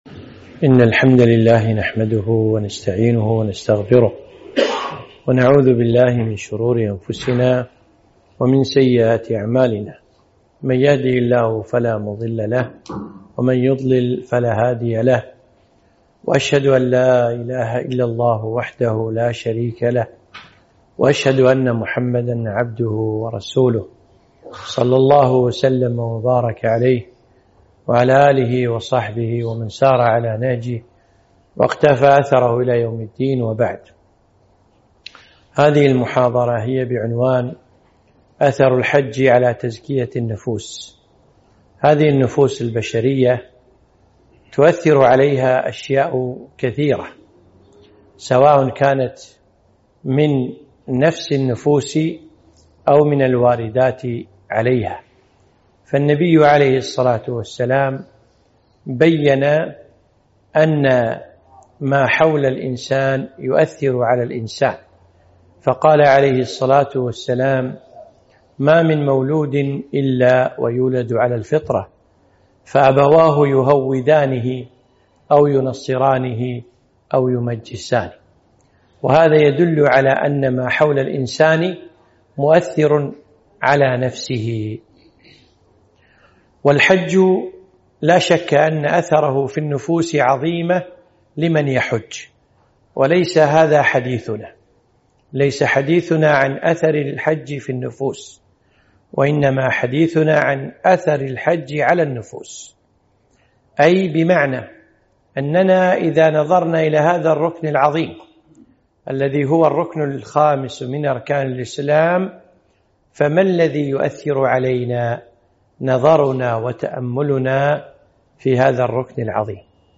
محاضرة - أثر الحج على تزكية النفوس